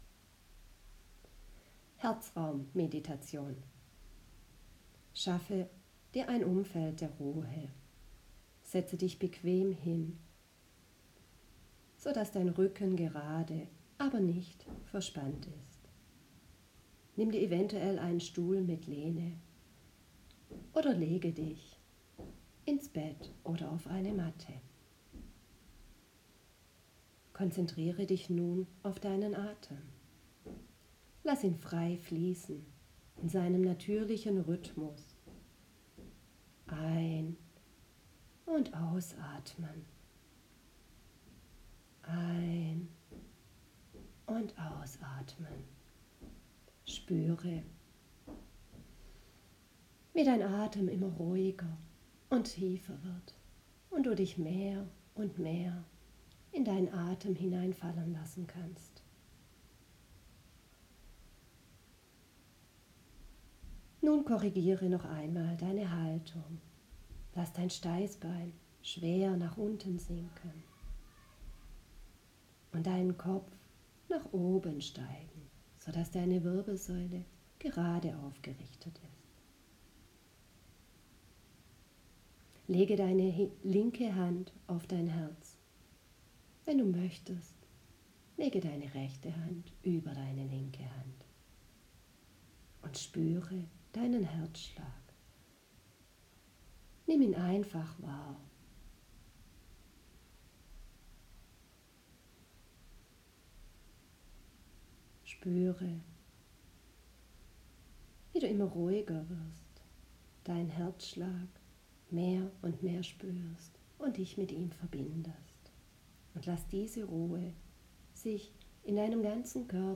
herzraummeditation15minuten